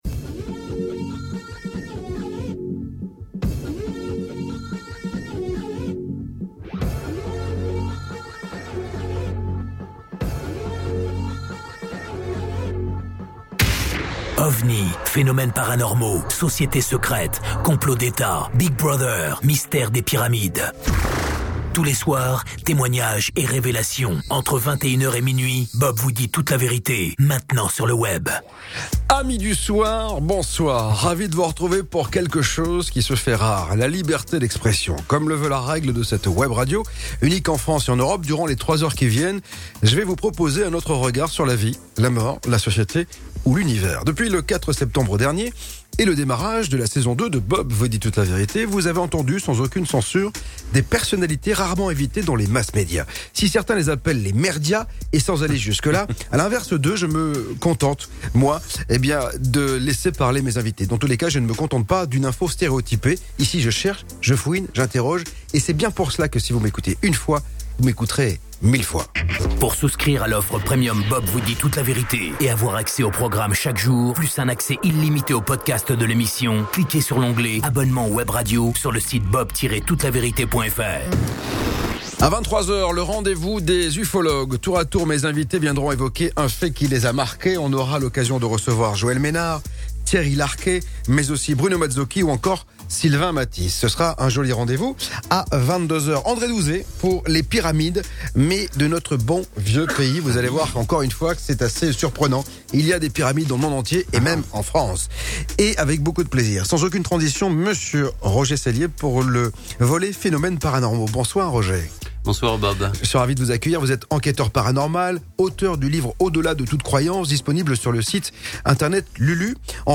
Vous pouvez écouter mes interviews radiophoniques : interview 1, interview 2, interview 3, interview 4, interview 5, interview 6.